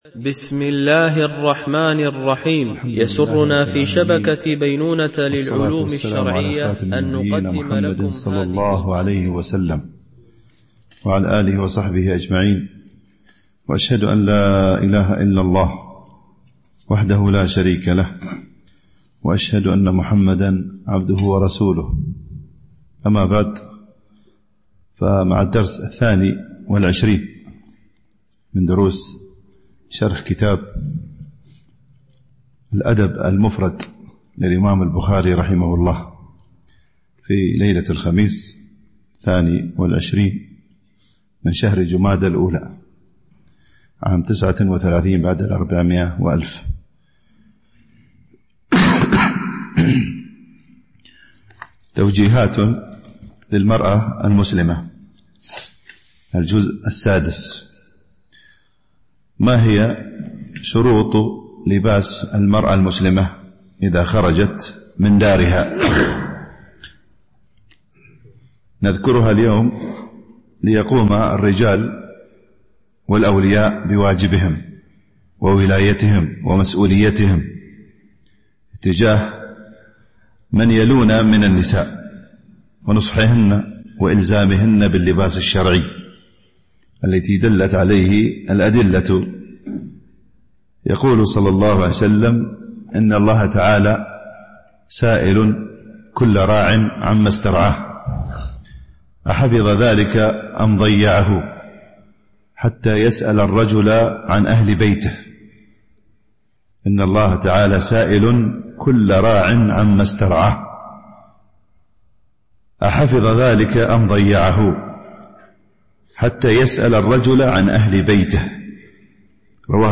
شرح الأدب المفرد للبخاري ـ الدرس 22 ( الحديث 112-118 )